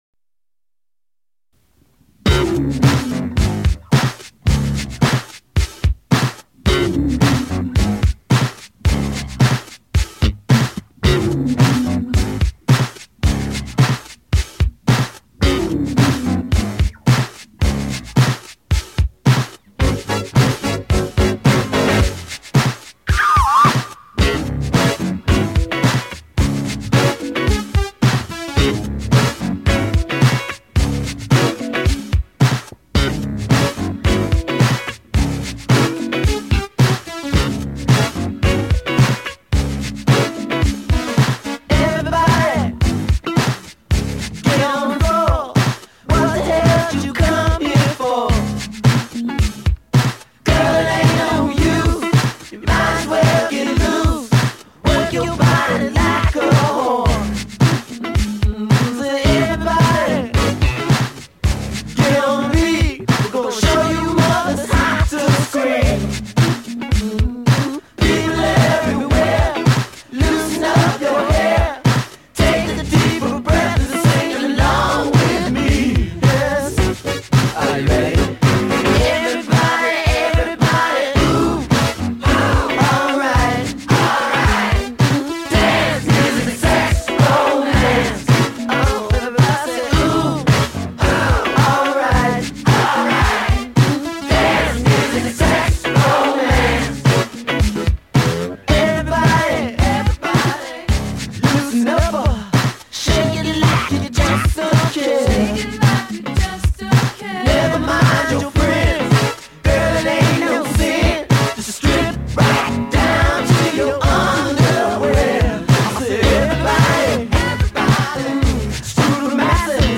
Recorded at Sunset Sound - Hollywood, CA.